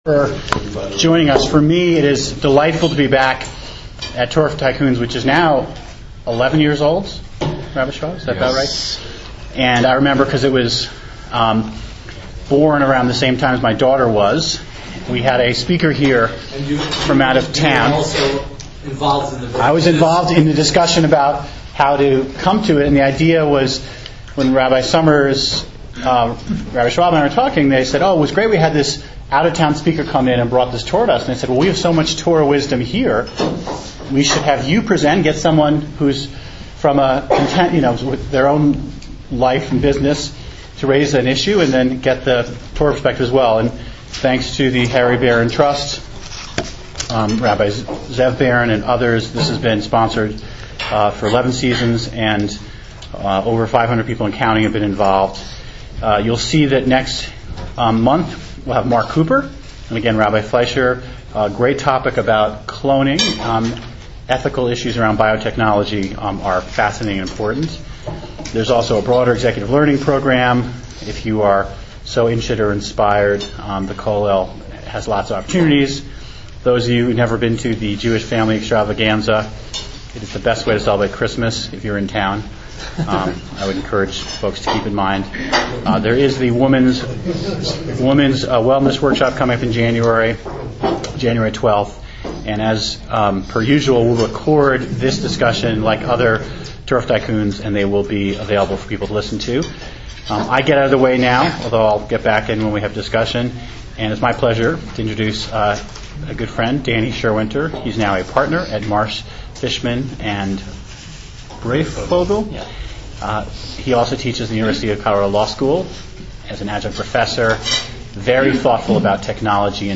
The session was moderated by Philip Weiser, and had 15 participants.